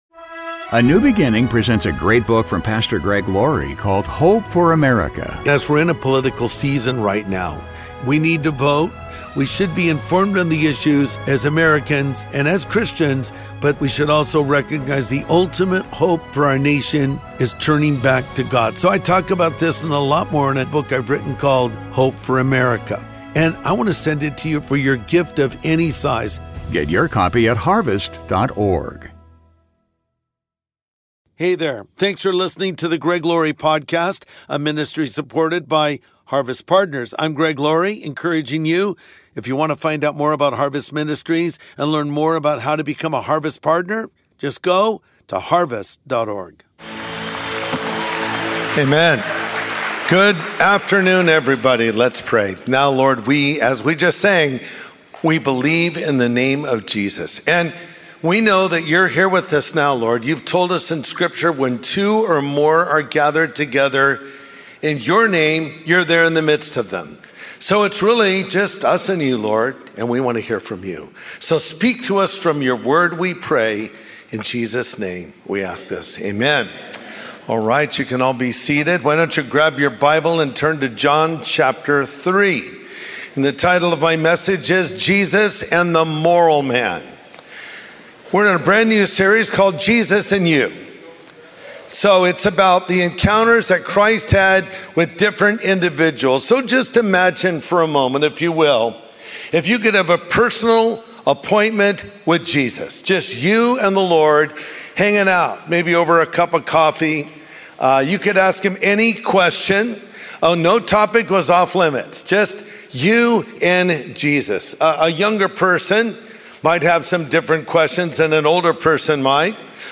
Nicodemus was a highly intelligent, cultured, and moral individual. In this message, Pastor Greg Laurie breaks down his conversation with Jesus.